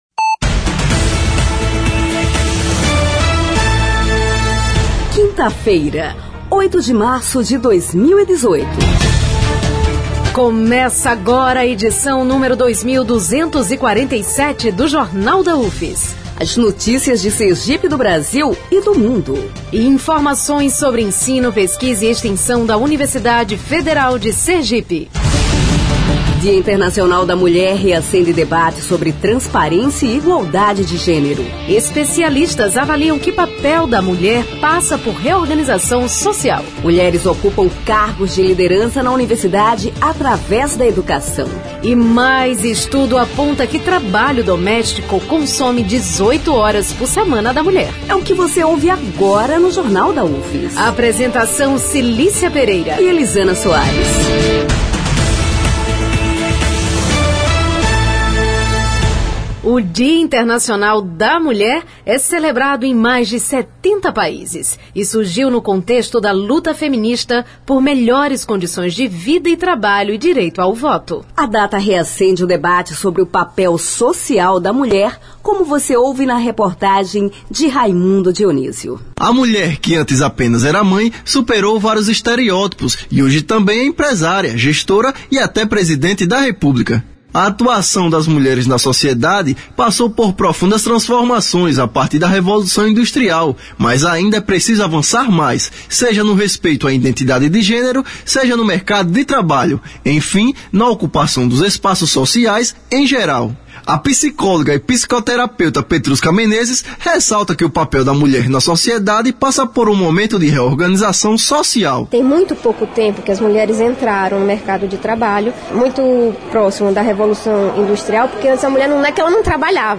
O noticiário vai ao ar às 11h00, com reprises às 17h00 e 22h00.